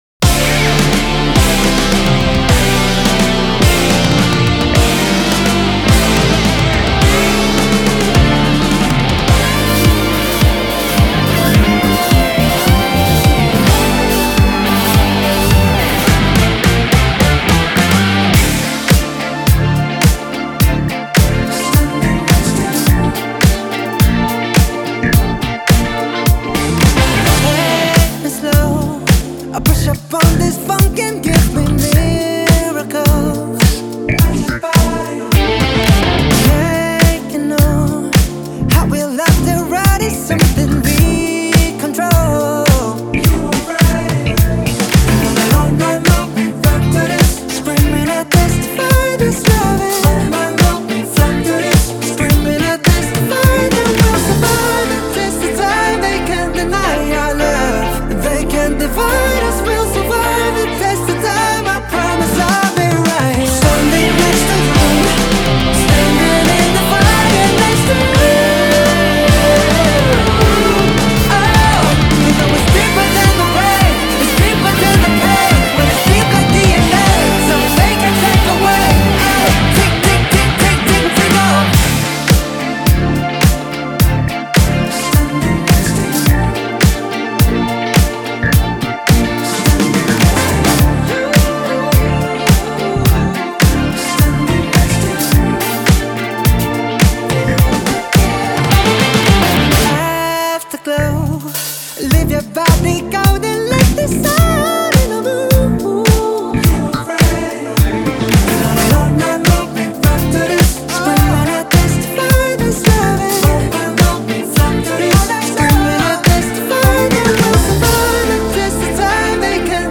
♪ ورژن بند Band Ver ♪